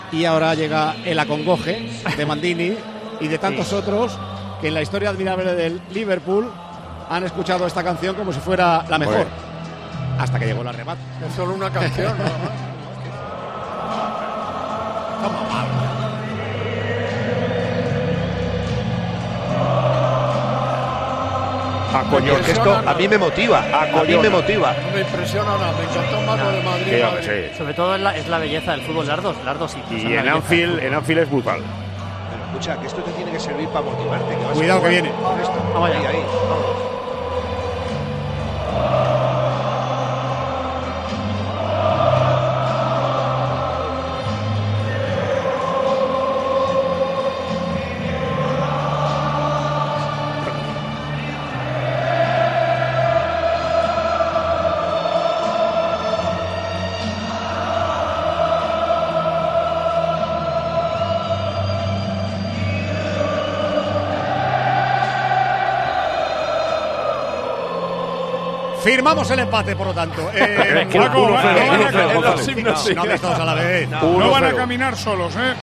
La afición del Liverpool canta 'You'll Never Walk Alone'